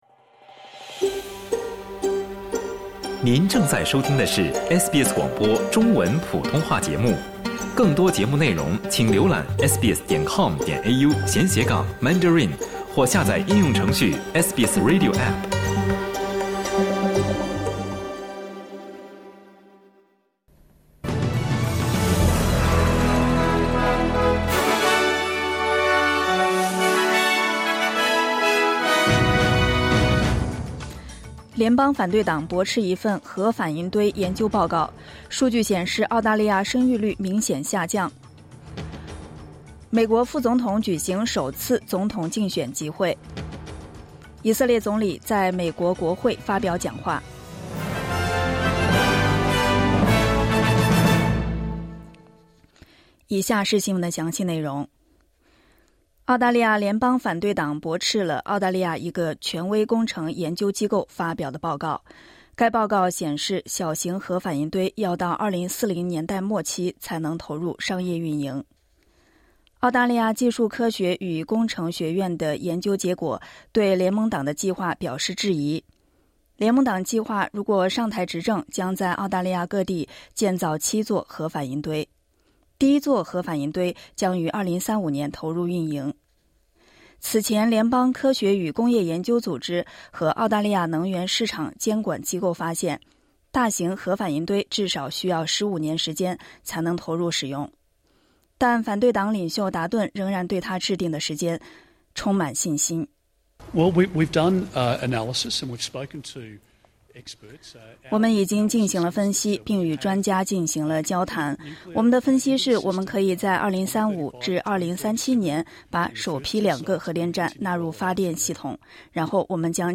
SBS早新闻（2024年7月25日）